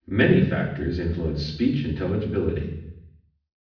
lecture800ms.wav